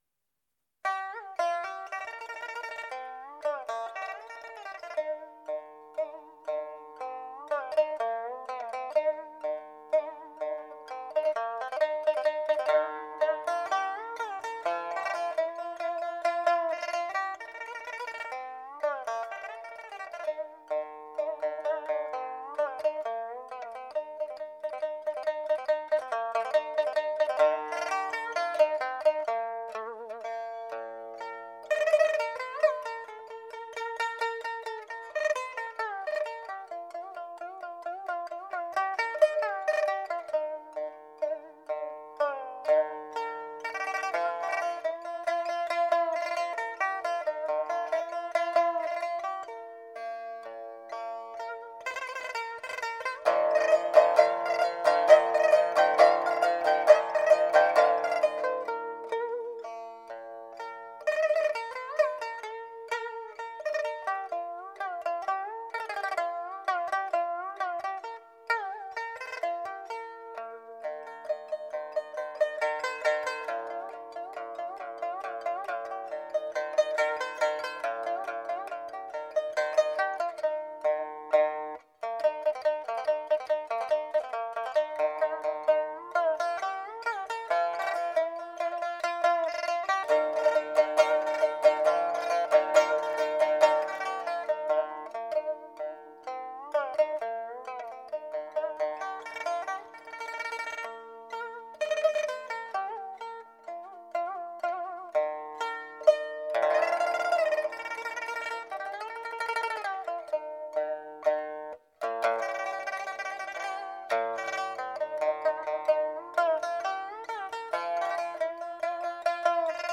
采用先进的数码录音技术